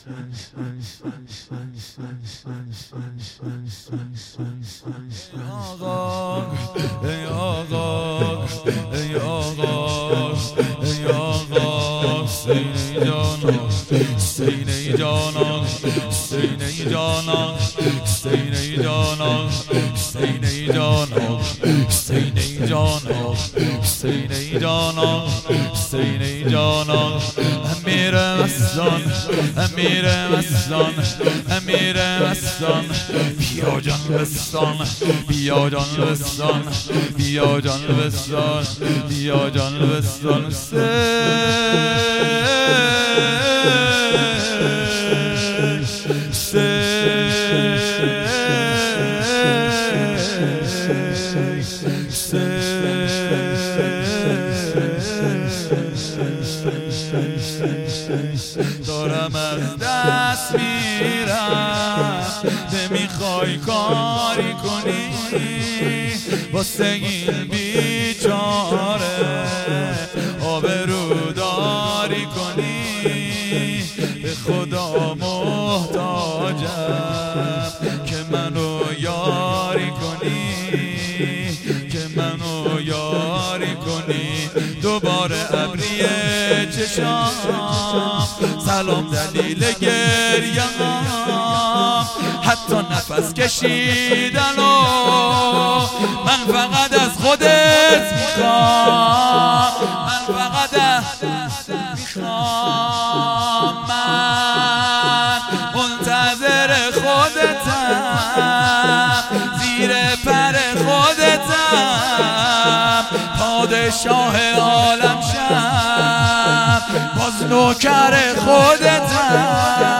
شب اول محرم الحرام 1399 بارعایت پروتکل های بهداشتی